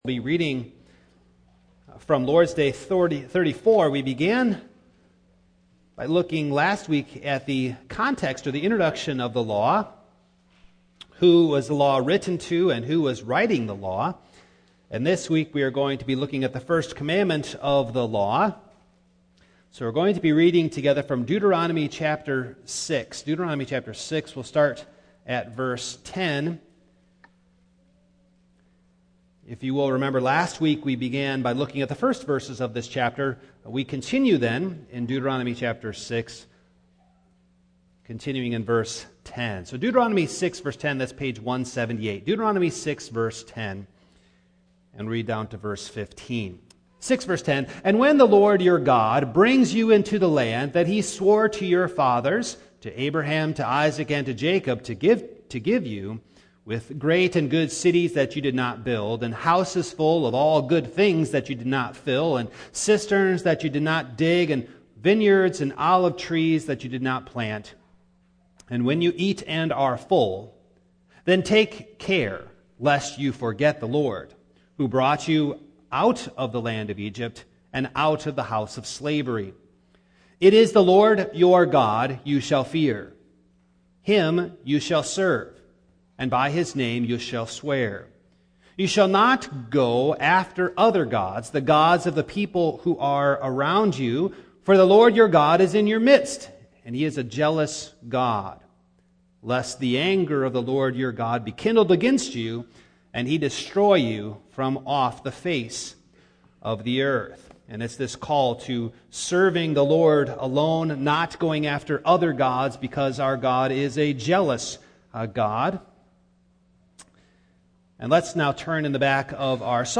Passage: Deut. 6:10-15 Service Type: Morning